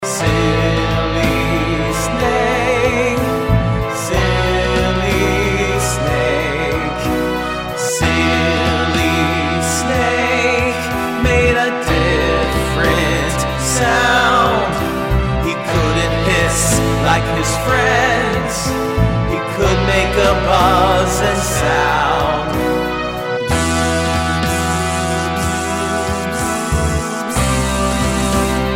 Vocal mp3 Song Track